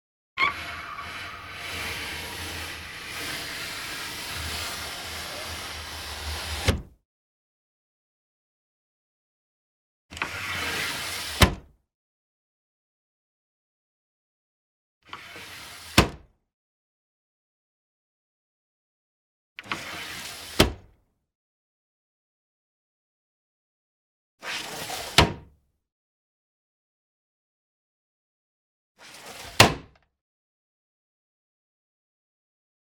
household
Sliding Wood Framed Window Close